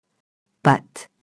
pâte [pɑt]